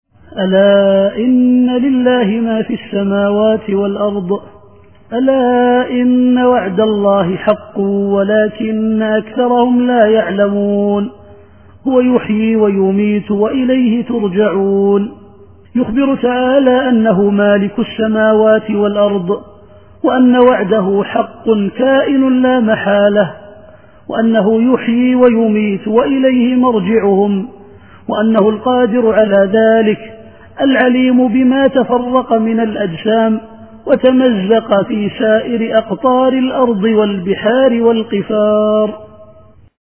التفسير الصوتي [يونس / 55]